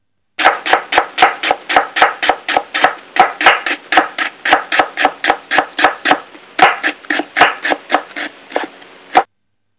chopping.wav